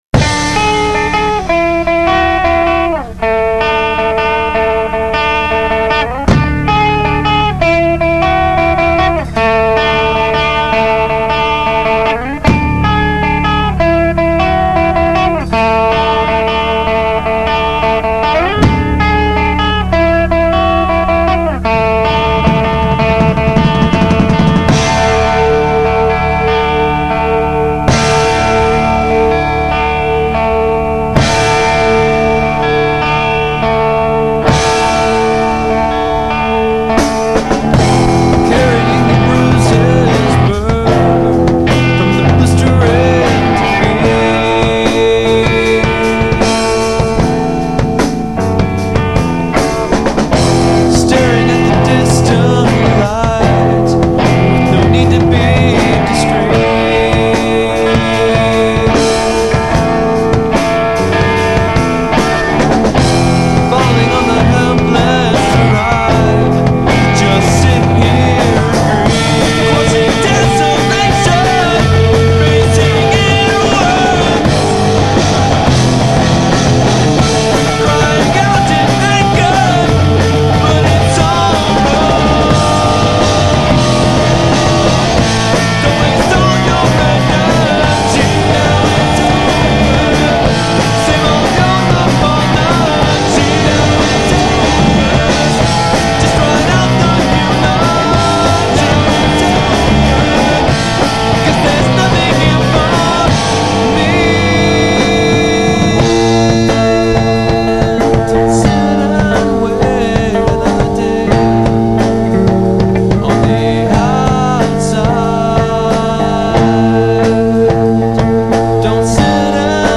One of those practice tapes revealed a song that we'd forgotten, that I wrote lyrics and recorded vocals for.
We were an emo band, it sounds like an emo song.